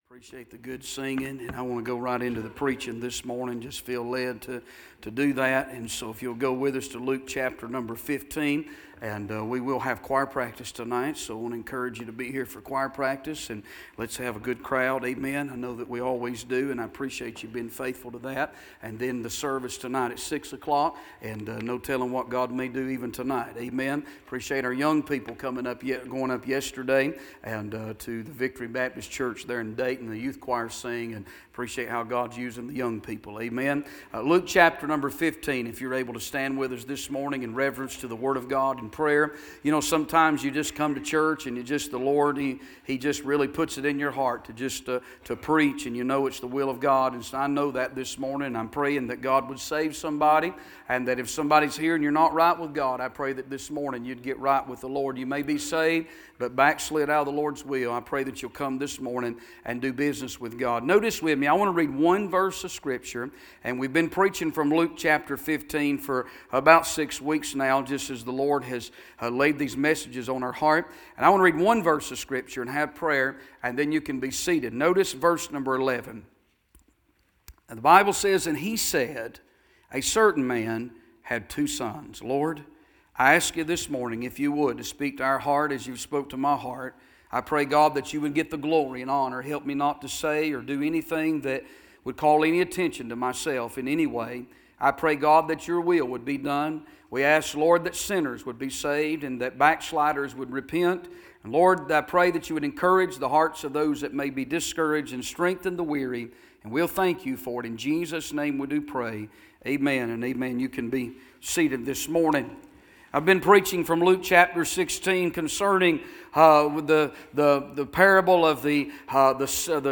Sermons - Bible Baptist Church